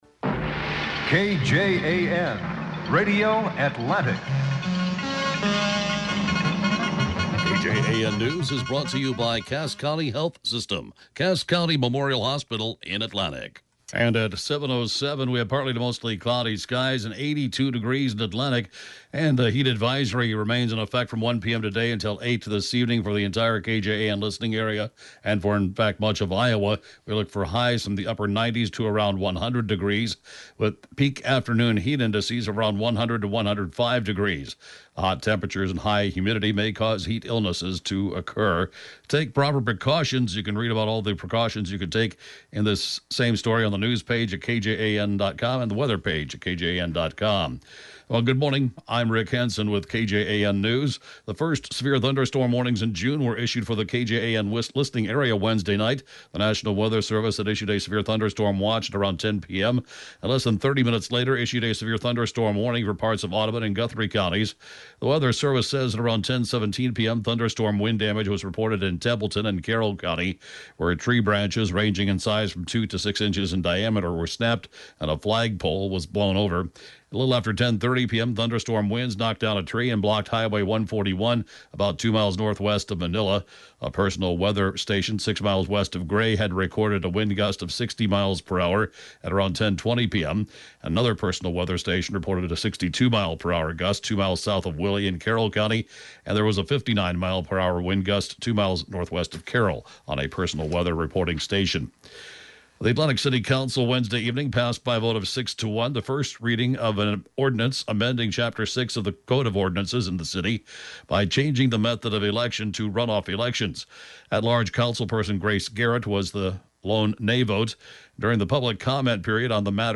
KJAN News can be heard at five minutes after every hour right after Fox News 24 hours a day!